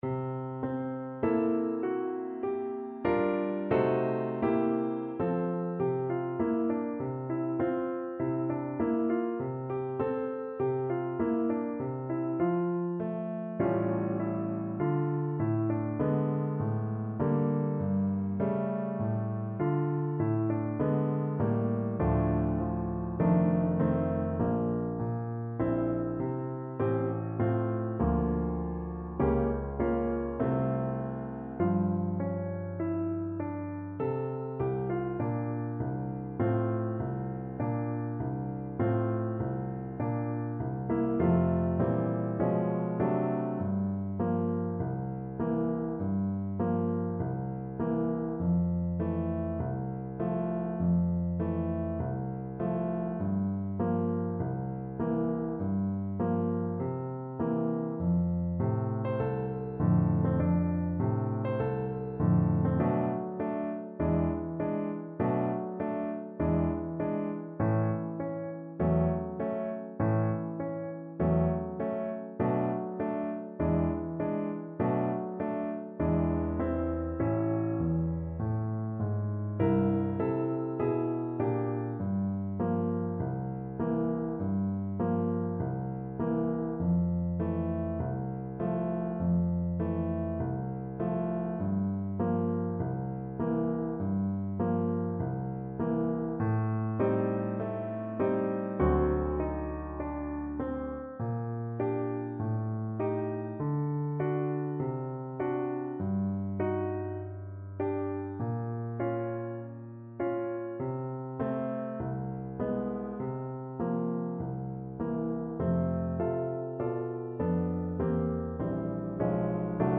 Moderato
4/4 (View more 4/4 Music)